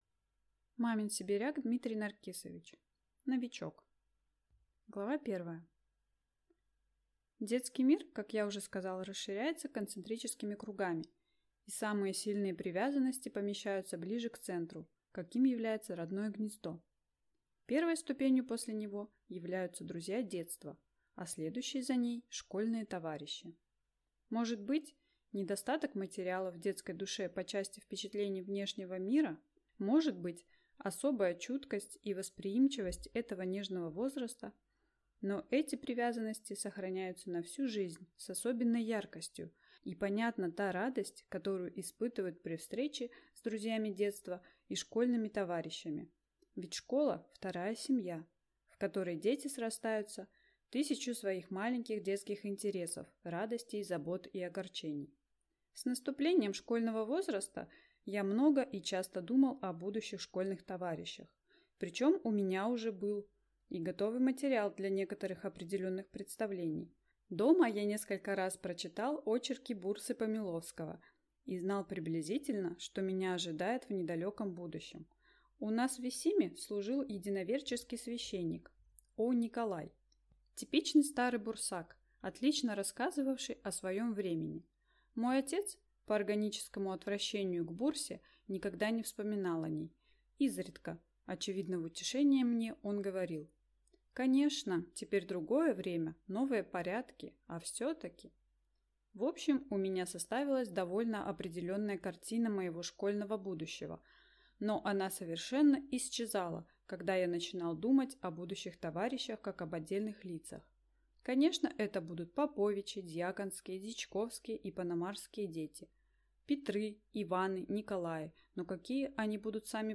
Aудиокнига Новичок